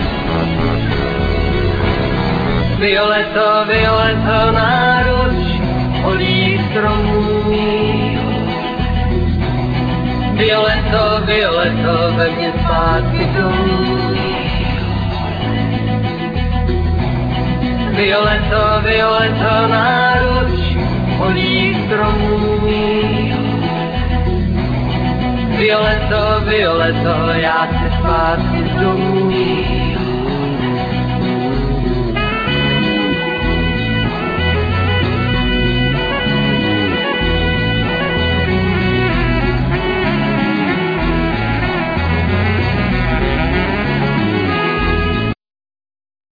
Vocal,Mandolin,E+A.Guitar
Saxophone,Clarinet,Whistle
Bass,Cello,Violin
Keyboards